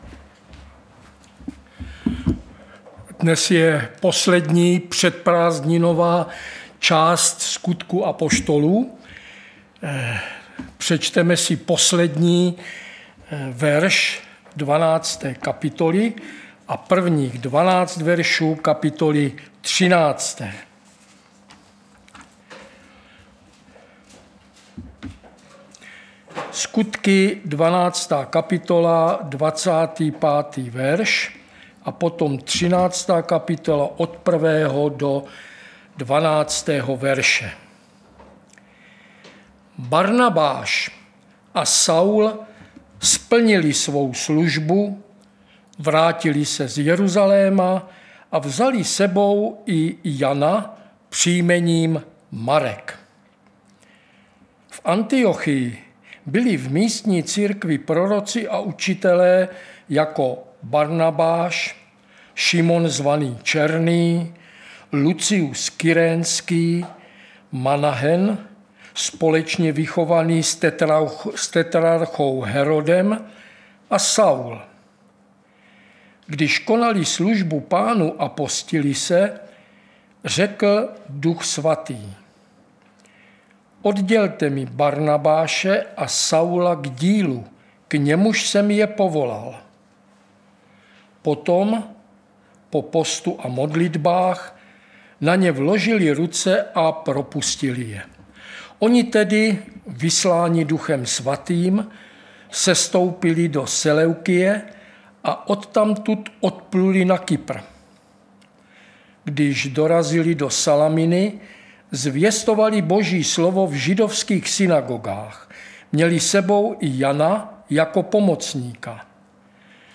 Středeční vyučování